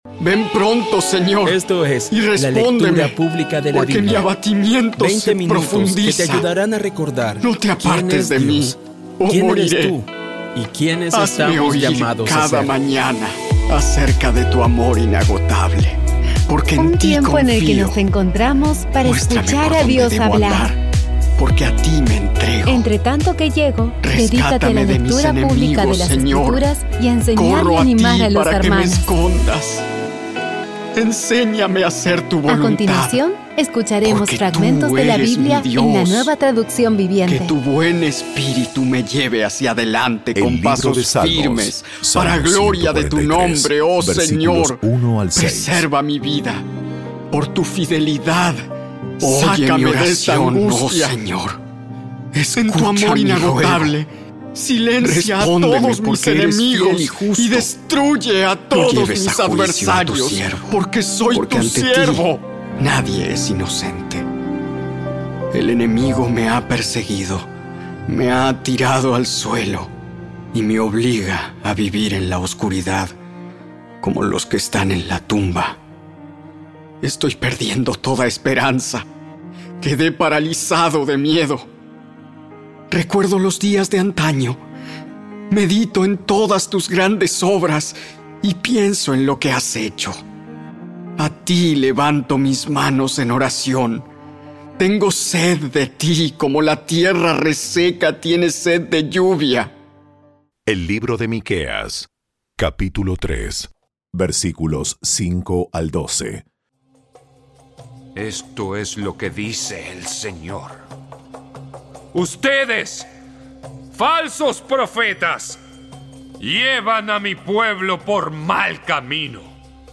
Audio Biblia Dramatizada Episodio 351
Usa esta maravillosa herramienta para crecer en tu jornada espiritual y acercarte más a Dios. Poco a poco y con las maravillosas voces actuadas de los protagonistas vas degustando las palabras de esa guía que Dios nos dio.